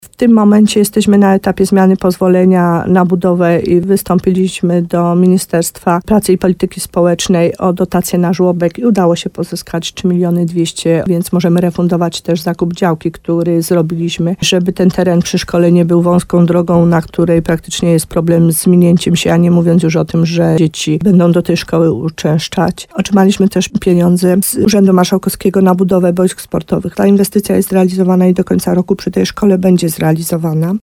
Wójt gminy Podegrodzie Małgorzata Gromala, przekonywała w programie Słowo za Słowo w radiu RDN Nowy Sącz, że brak oficjalnego statusu o likwidacji szkoły na czas budowy powoduje, że trzeba dopłacać do niej co roku 2 mln złotych.